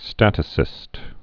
(stătə-sĭst)